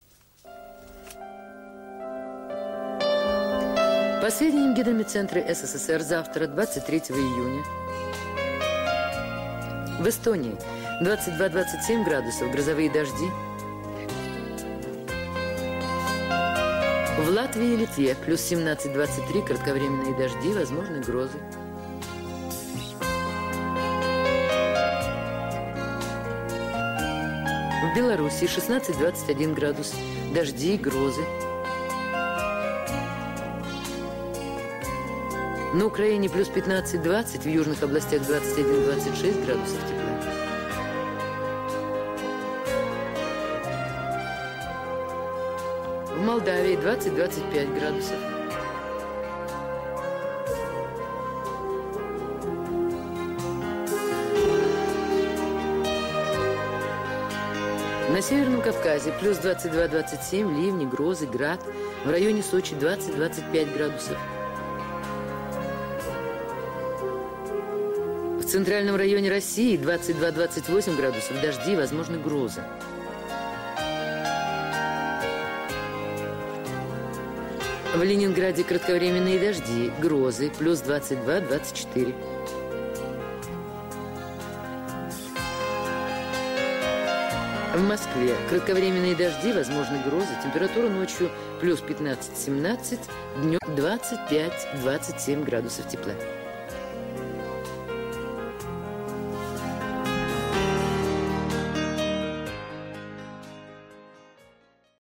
Звук прогноза погоды с голосом диктора СССР 23 июня 1988 года